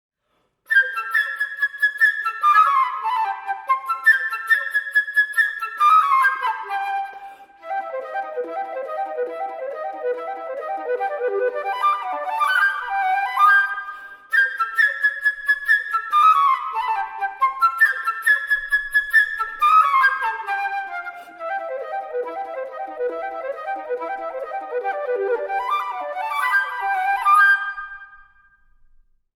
Dwarsfluit